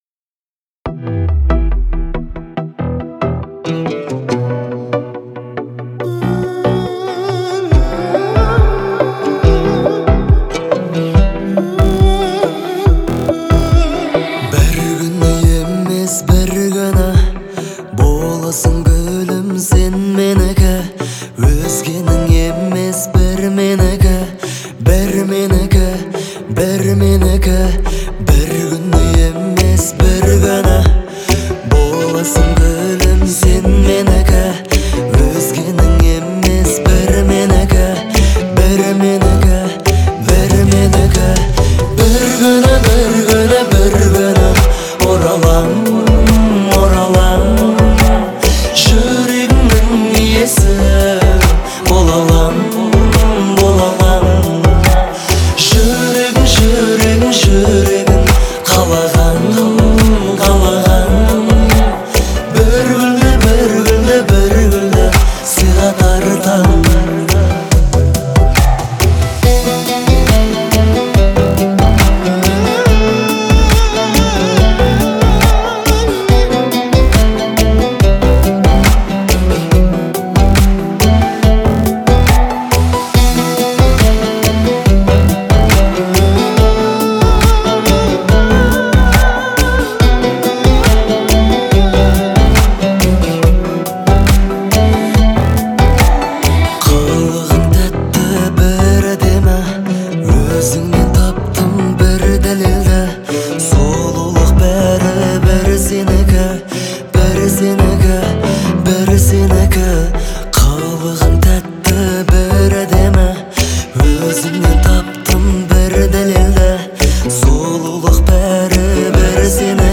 Звучание трека отличается мелодичностью и душевностью